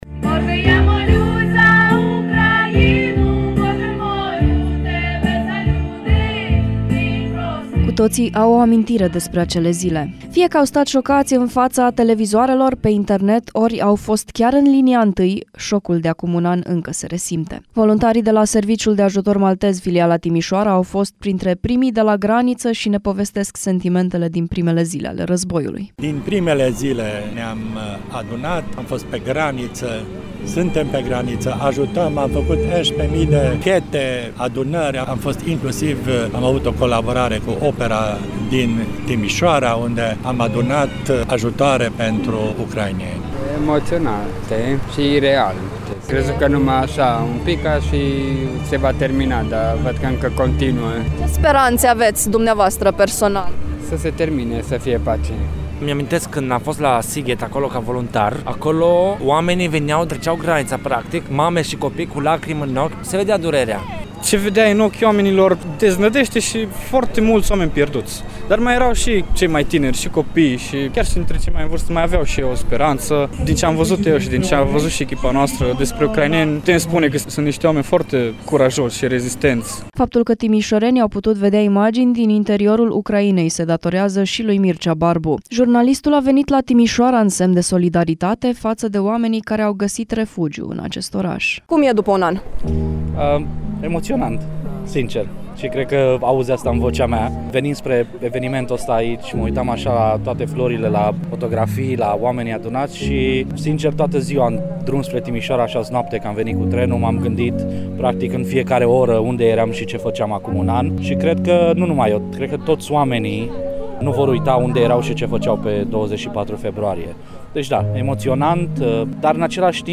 Câteva sute de persoane s-au reunit în Piața Victoriei pentru a marca un an de la declanșarea războiului împotriva Ucrainei.
A fost păstrat un moment de reculegere în amintirea victimelor războiului și s-au rostit rugăciuni în ucraineană, română, maghiară, germană.